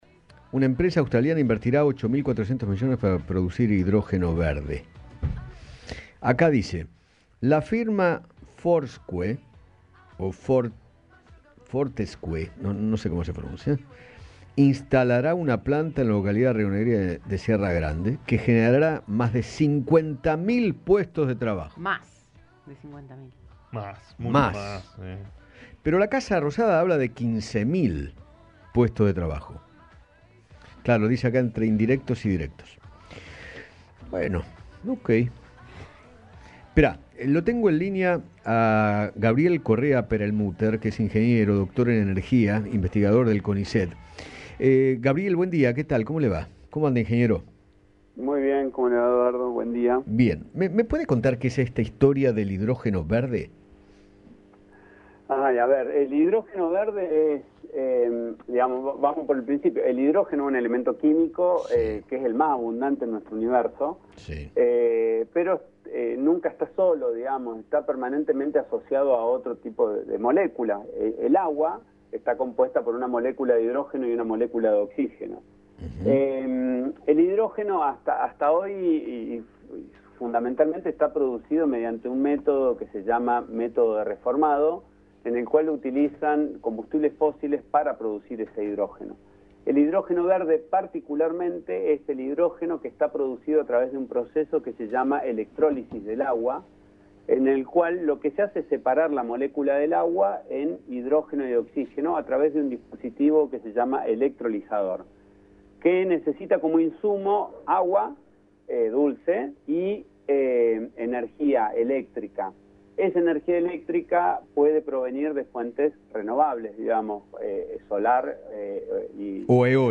¿Que-es-el-hidrogeno-verde-Radio-Rivadavia-AM630.mp3